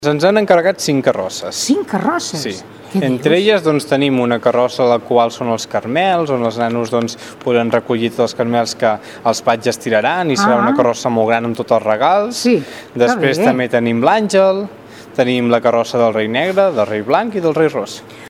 El regidor de festes, Aniol Canals, ho comenta.